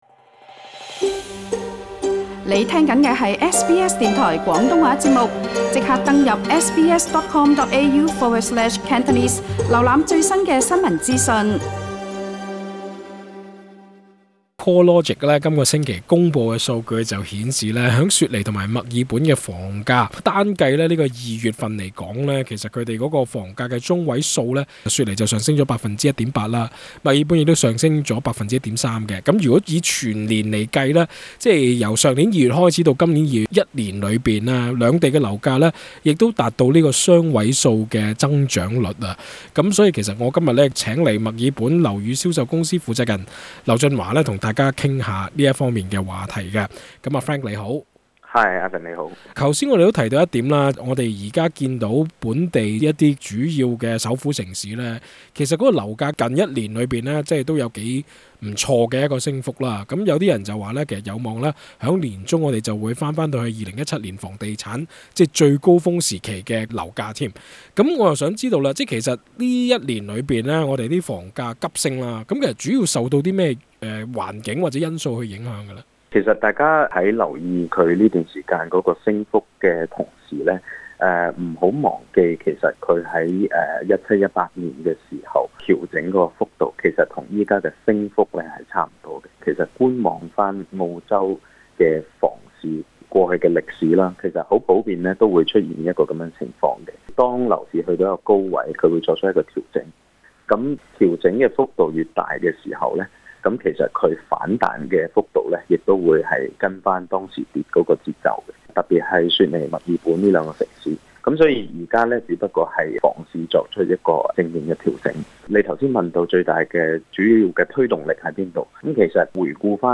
請收聽本台足本訪問。